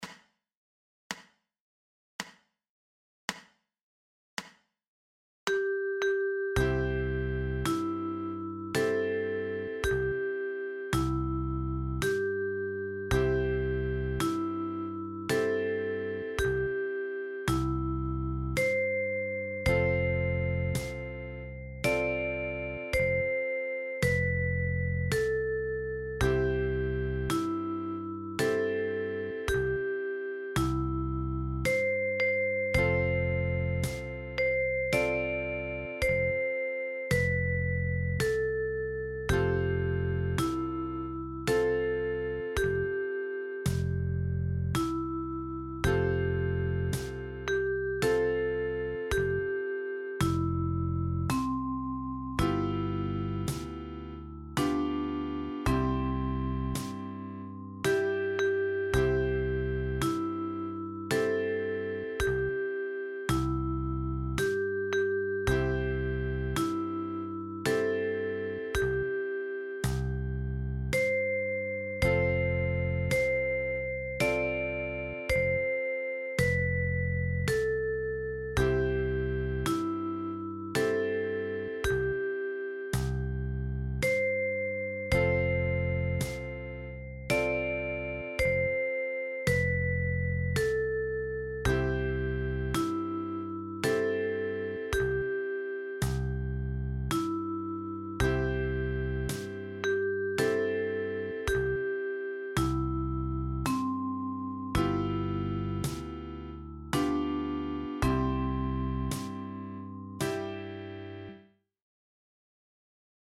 für die Okarina mit 6 Löchern